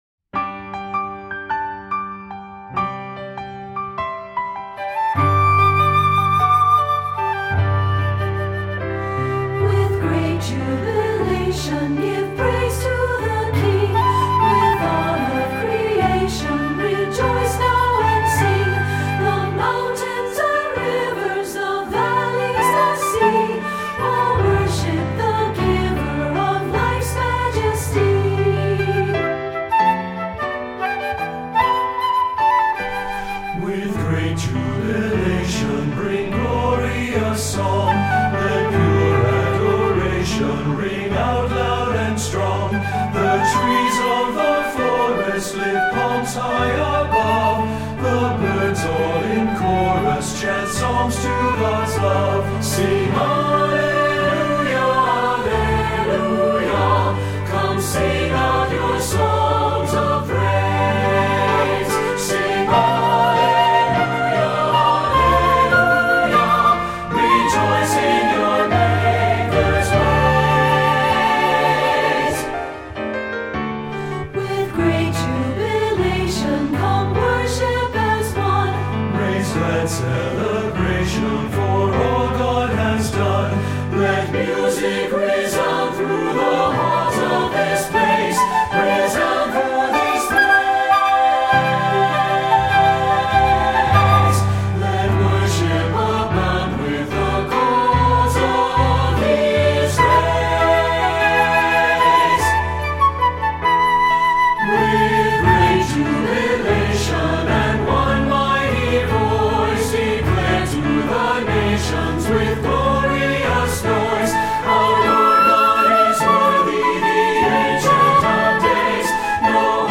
Voicing: SATB and Flute